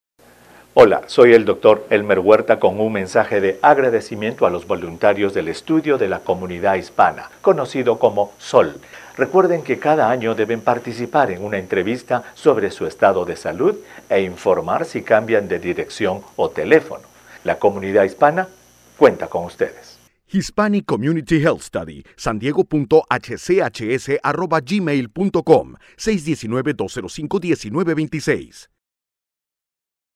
The Audio files at the bottom of the page are the same voice overs presented in each video.
Comunicado de Prensa audio - 30 sec en Español